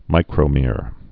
(mīkrō-mîr)